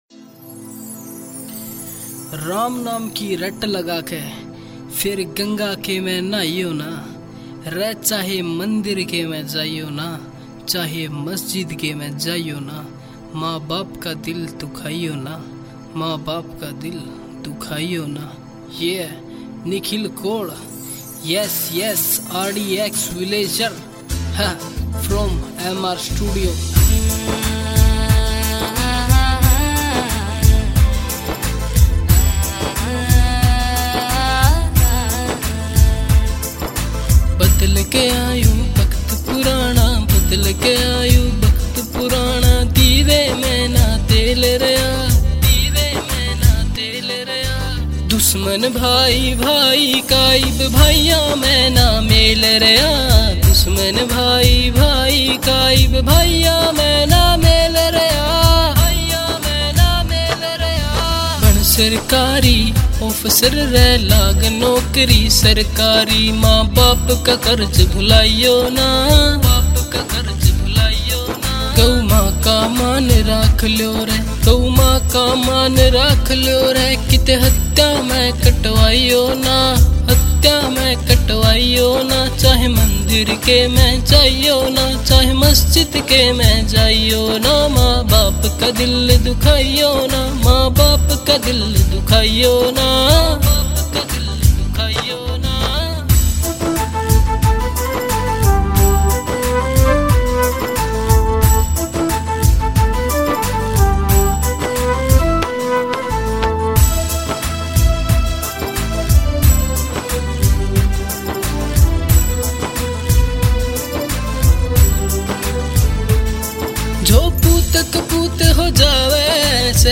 » Haryanvi Songs